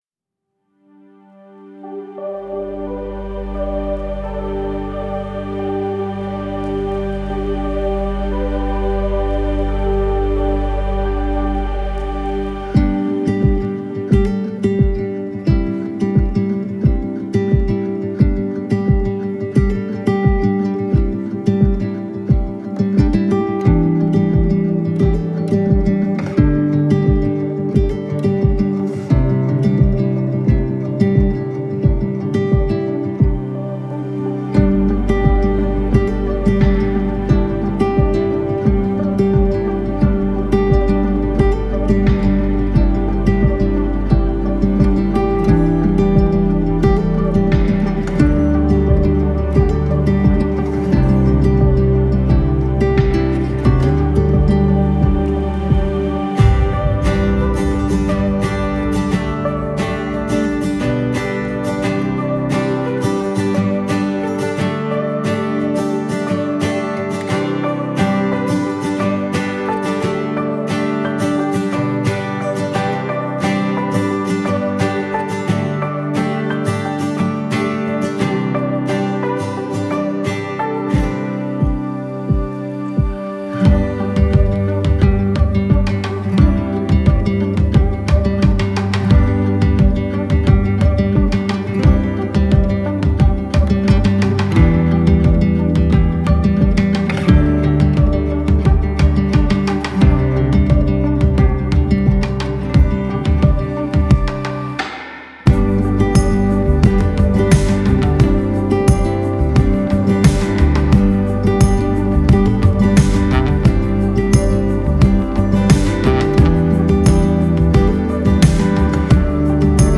instrumental playback
Gemensam sång